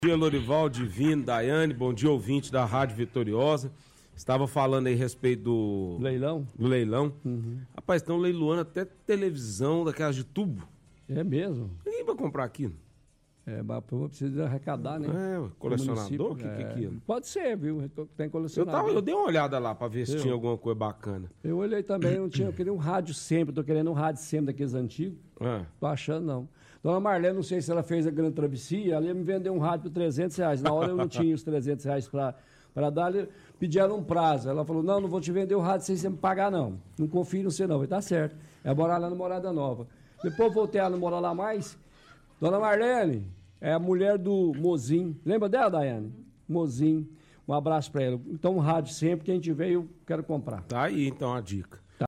Conversa